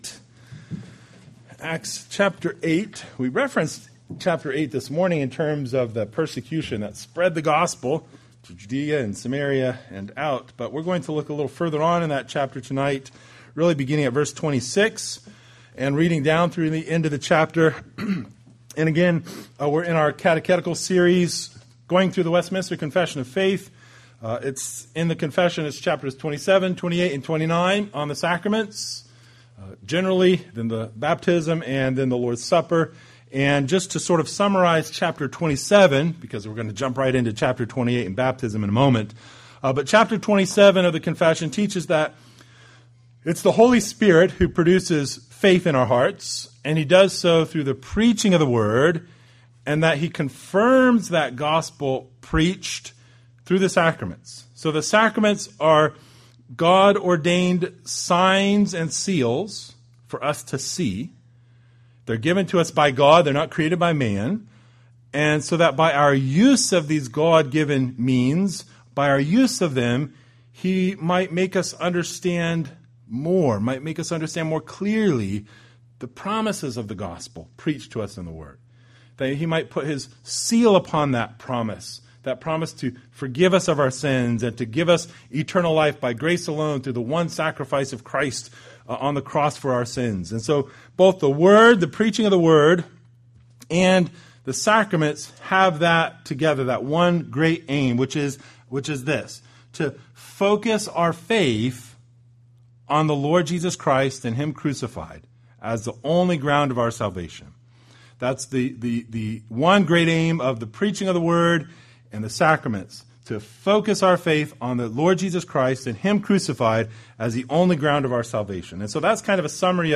The Westminster Confession of Faith Passage: Acts 8:26-40 Service Type: Sunday Evening Related « Pilgrims